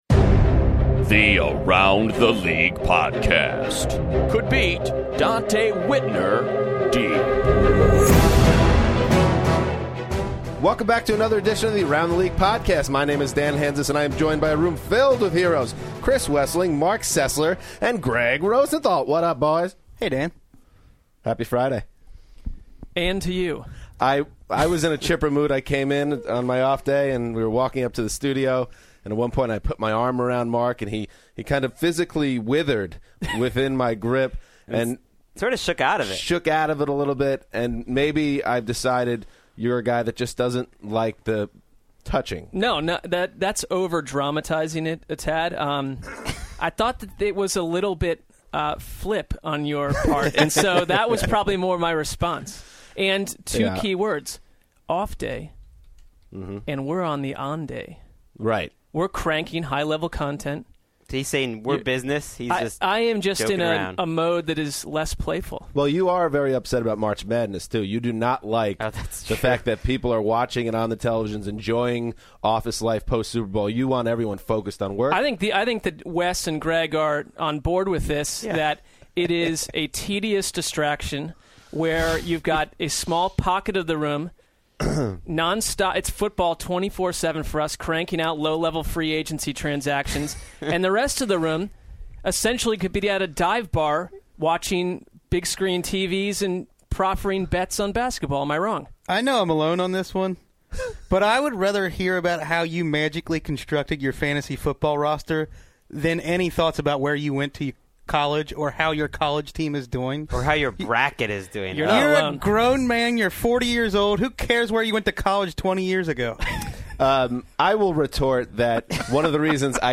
NFL ATL: Lance Briggs in studio, Running Back Roulette